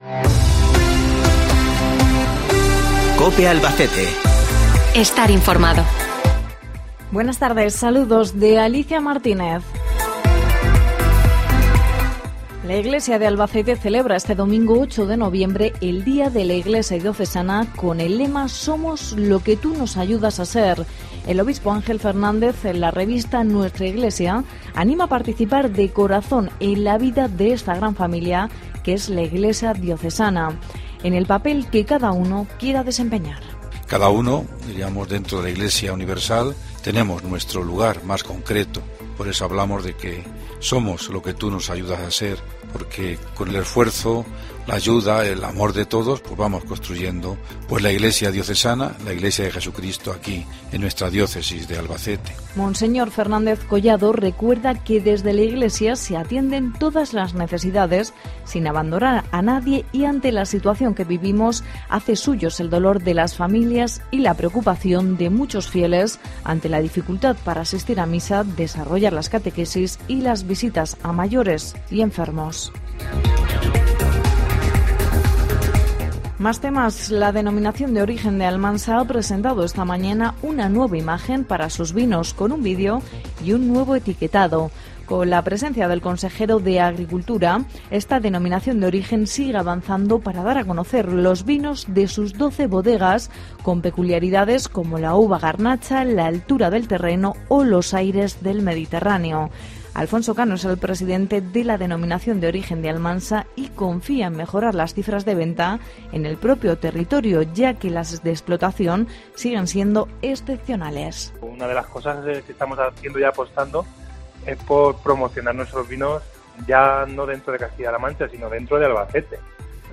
Informativo local 5 de noviembre